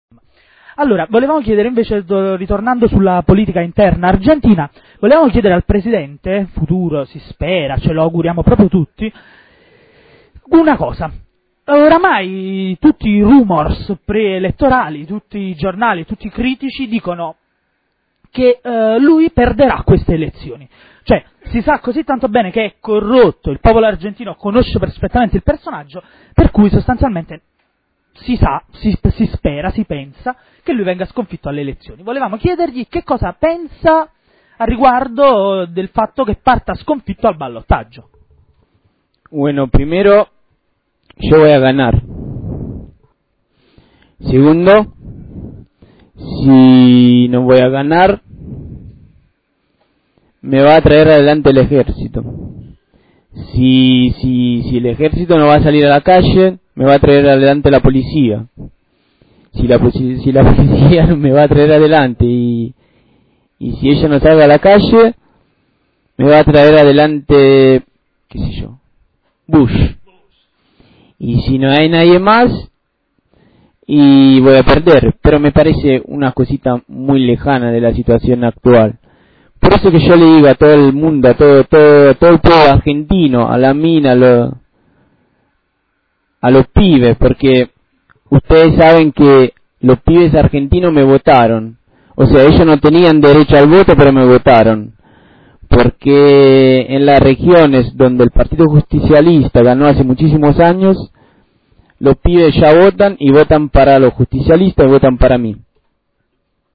Brano dell'intervista a Menem di Radio Barrio Maradona
Brano tratto dalla lunga intervista al candidato alle presidenziali argentine Menem, che si e' concesso ai microfoni di Radio Barrio Maradona durante la puntata di ieri Martedi 29, in cui spiega come pensa di fare per portare a termine la sua rimonta elettorale.